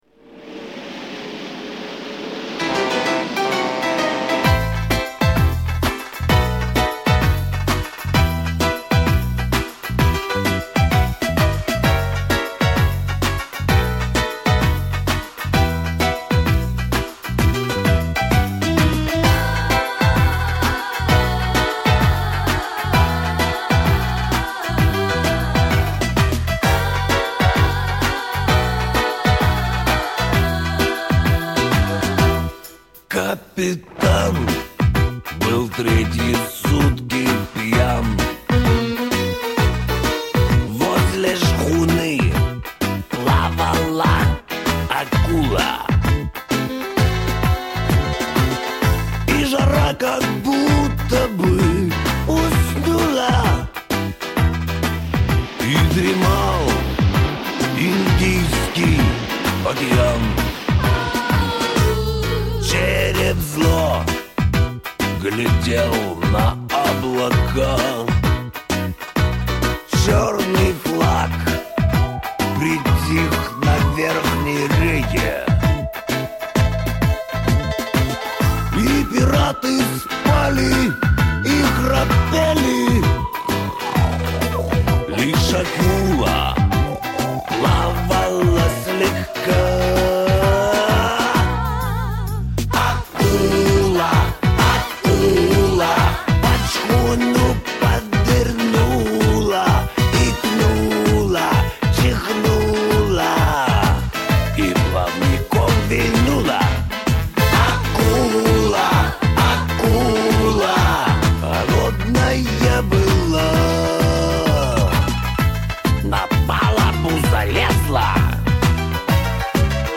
Это надо ж было с такой завлекательной хрипотцой спеть!